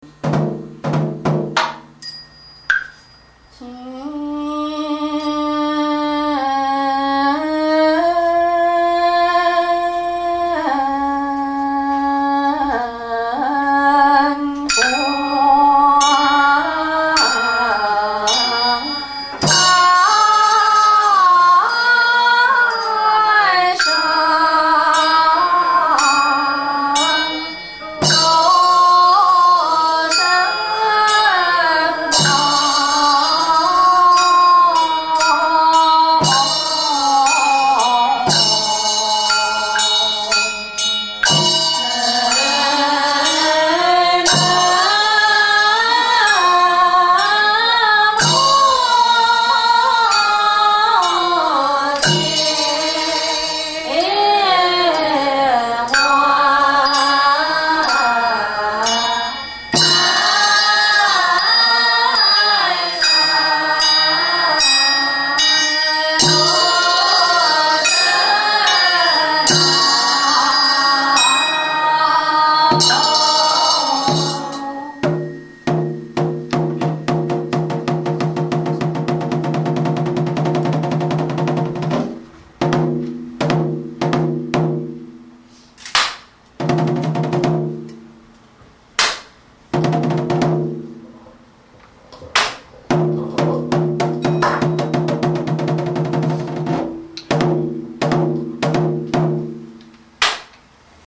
佛曲音樂 > 經典唱誦/法會佛事 > 瑜伽焰口